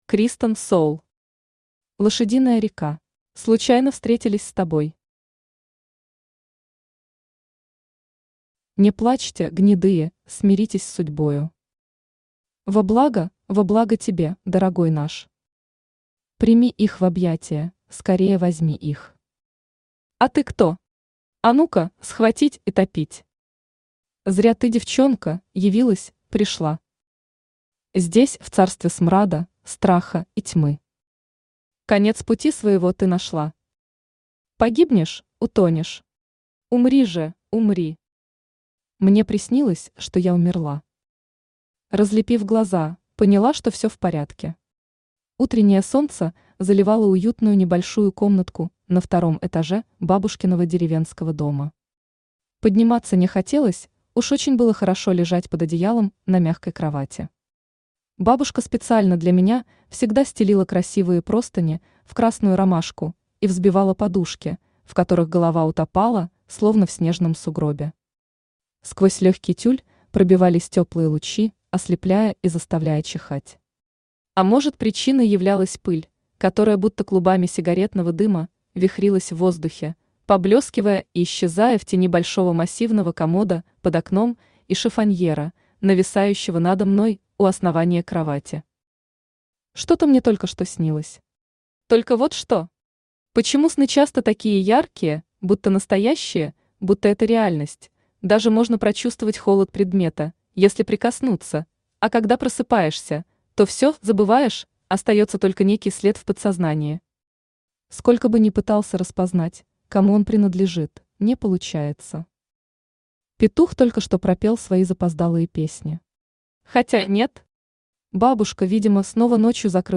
Aудиокнига Лошадиная река Автор Kristen Soul Читает аудиокнигу Авточтец ЛитРес.